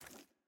sfx_ui_map_panel_close.ogg